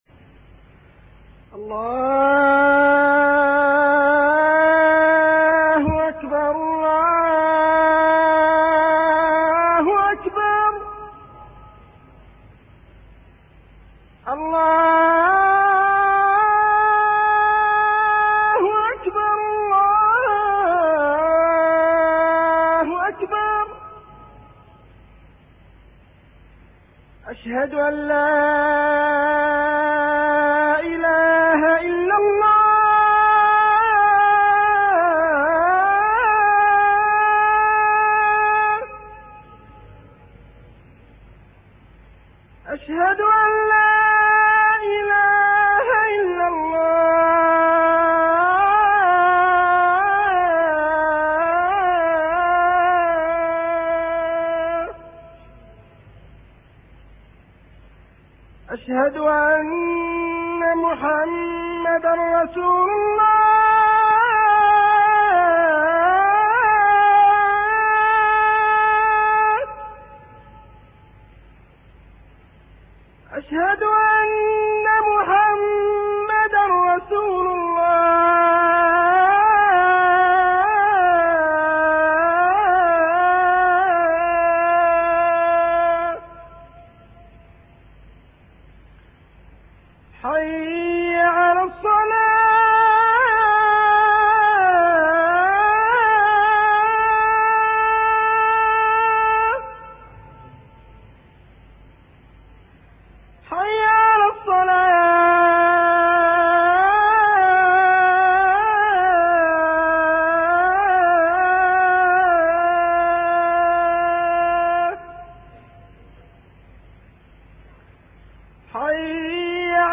أناشيد ونغمات
عنوان المادة أذان-12